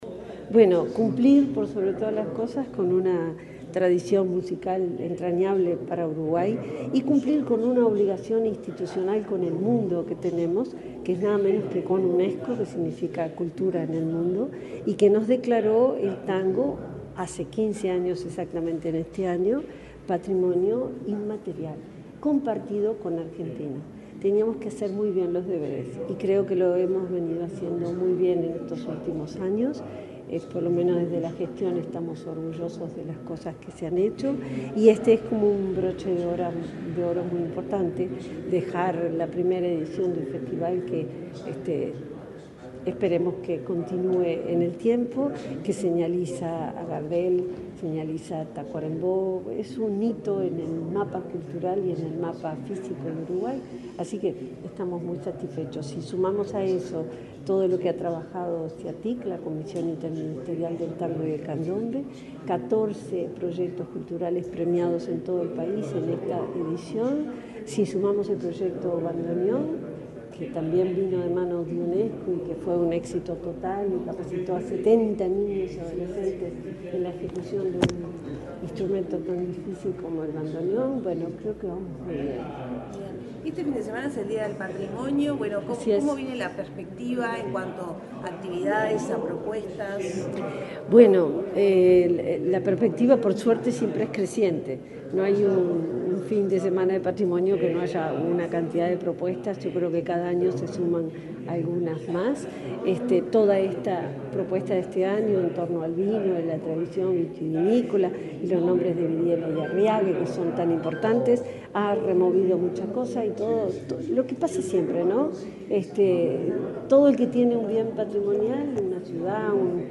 Declaraciones de la subsecretaria de Educación y Cultura, Ana Ribeiro
Este miércoles 2 en Montevideo, la subsecretaria de Educación y Cultura, Ana Ribeiro, dialogó con la prensa, luego de participar del lanzamiento del